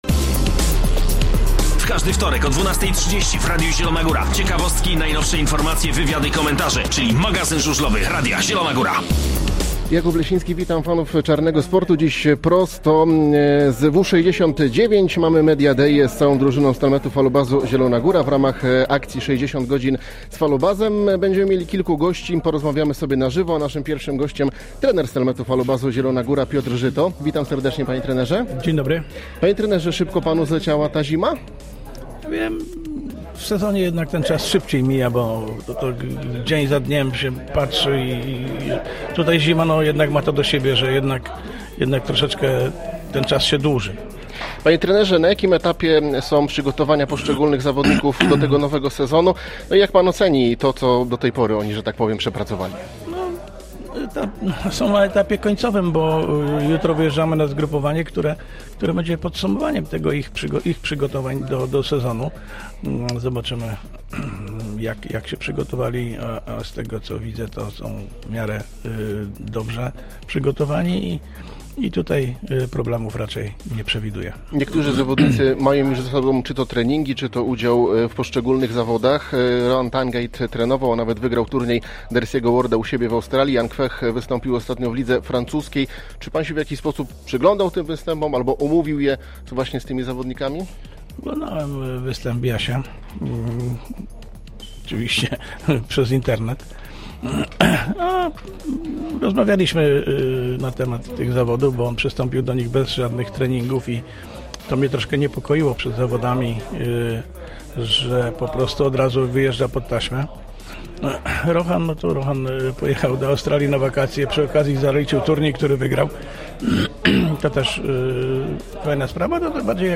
W magazynie wystąpili przedstawiciele Stelmetu Falubazu Zielona Góra: